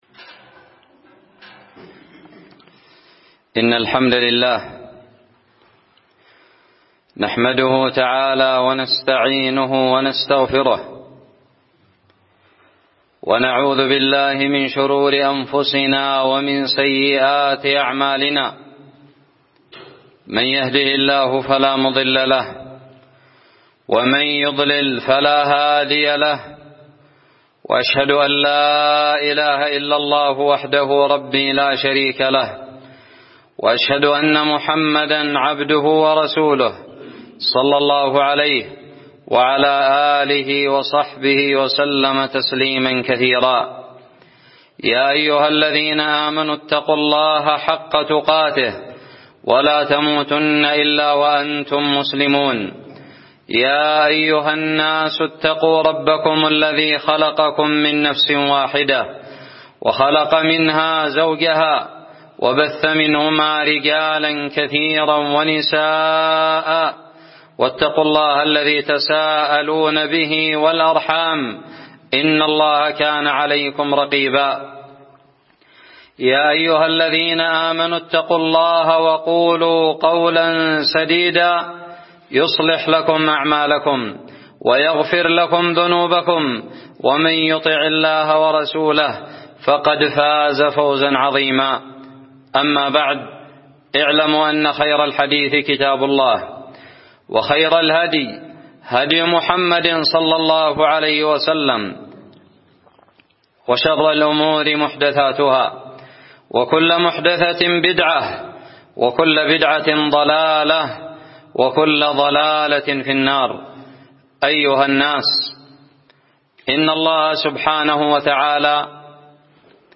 خطب الجمعة
ألقيت في جحاف – بني سعيد – 21 صفر 1439هــ